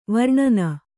♪ varṇana